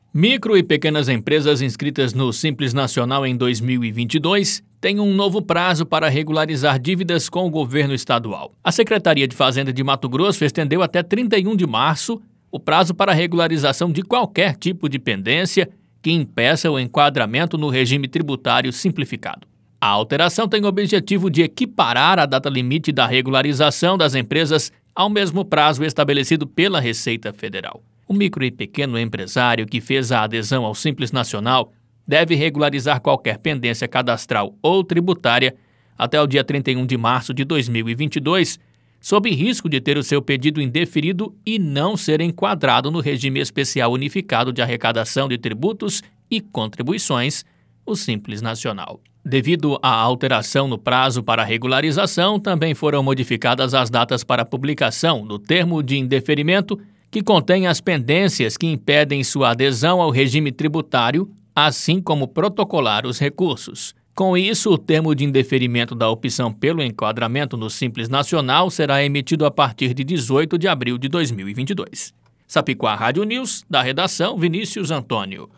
Boletins de MT 04 mar, 2022